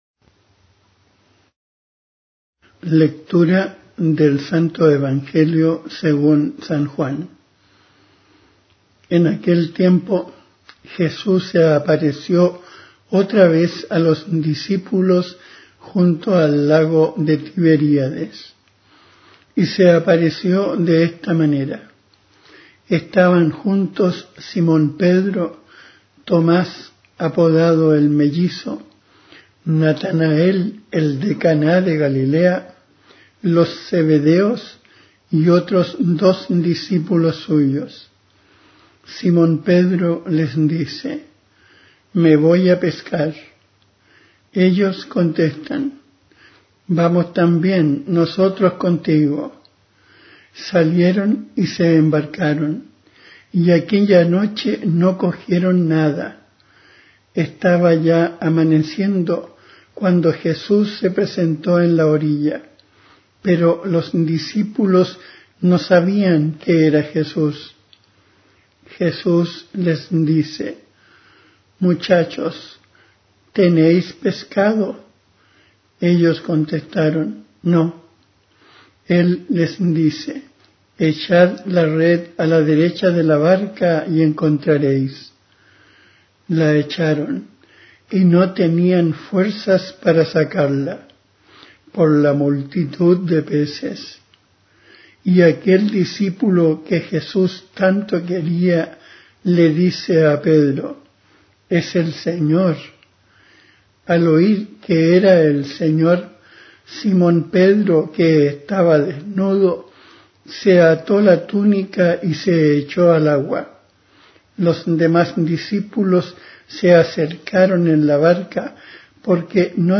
Lectura del libro de los Hechos de los apóstoles (5,27b-32.40b-41):